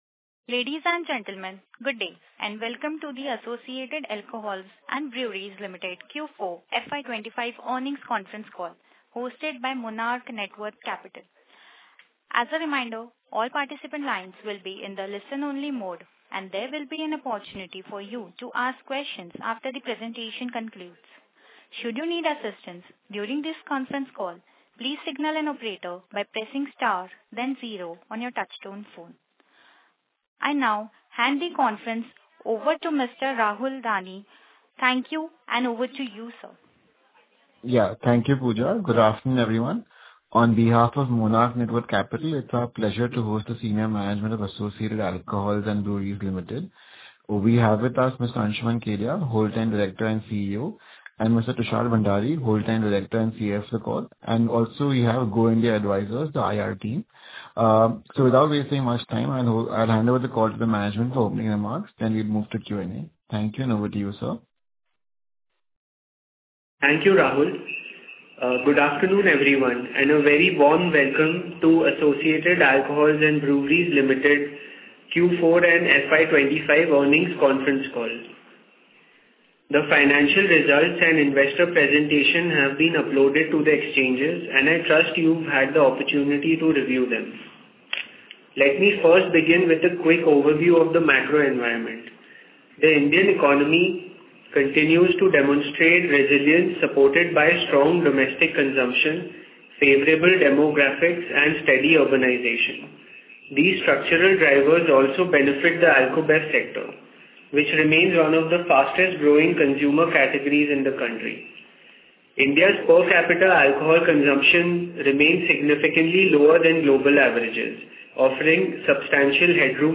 Concalls
Q4FY25-Earnings-Con-call-AABL.mp3